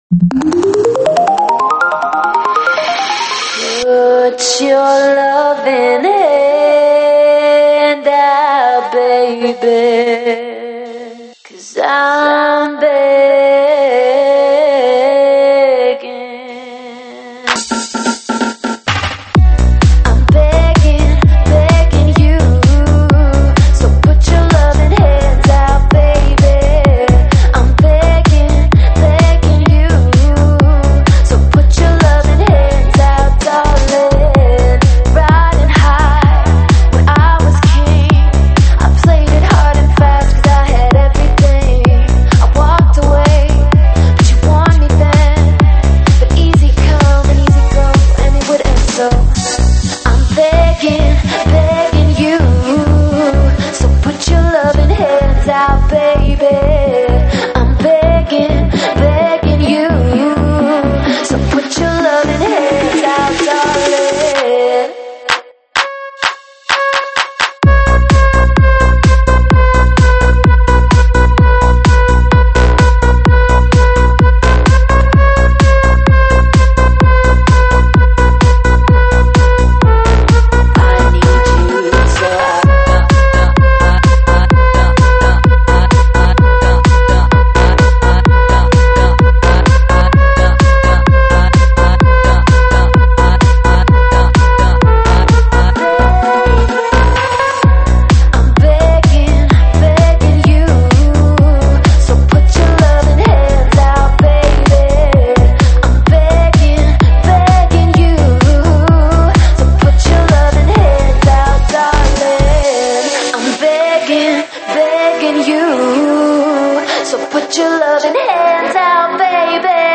舞曲类别：独家发布